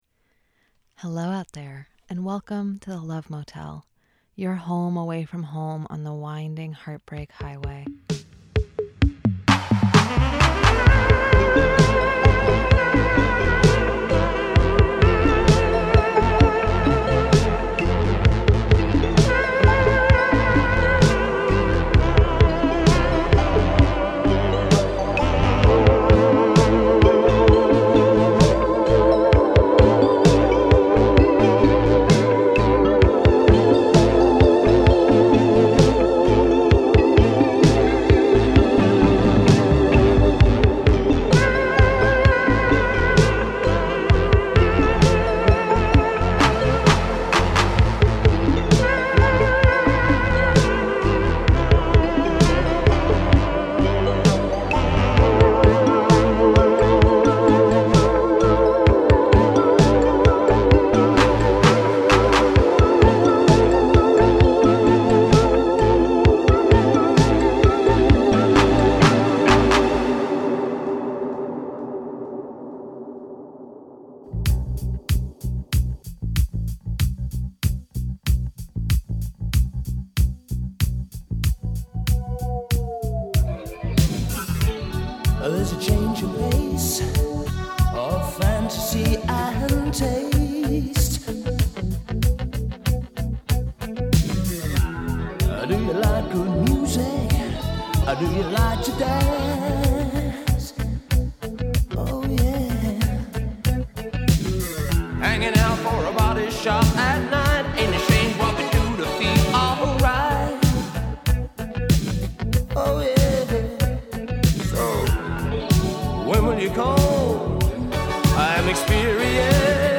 The Love Motel is a monthly radio romance talk show with love songs, relationship advice, and personals for all the lovers in the upper Hudson Valley.